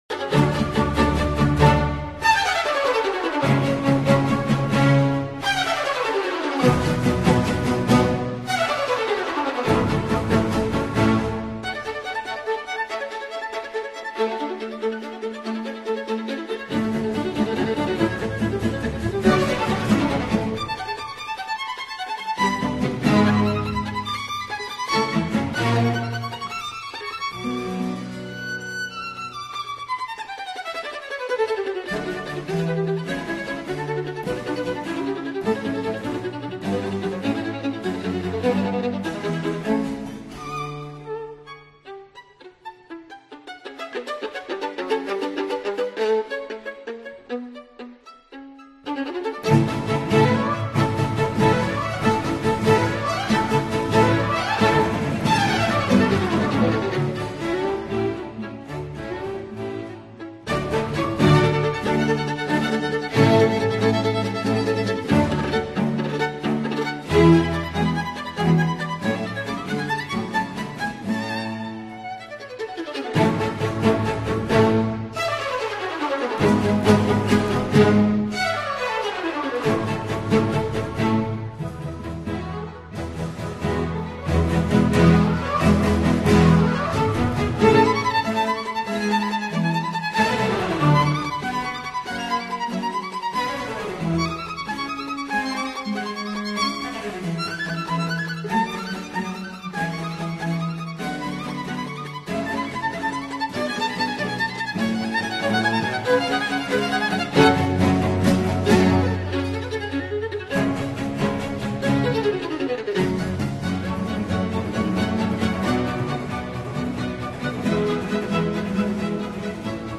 Orchestre de chambre